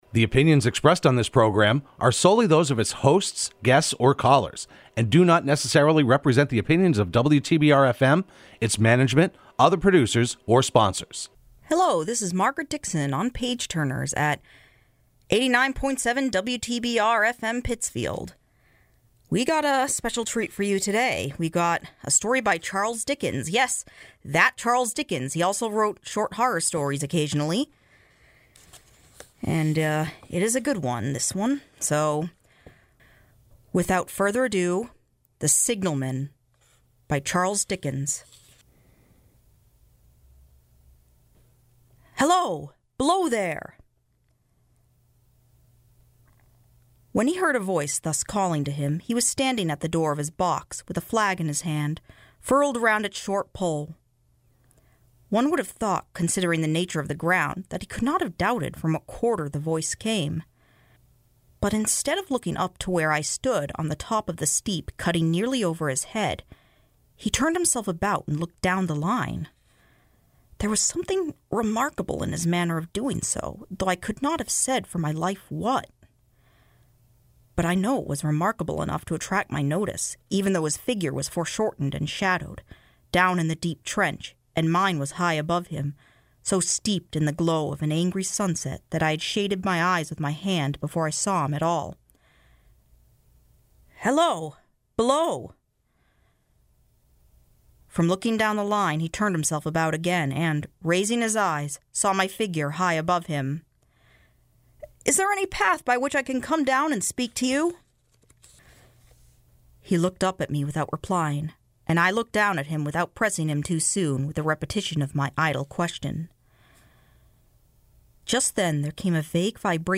Page Turners can be heard every Sunday morning at 7am on WTBR.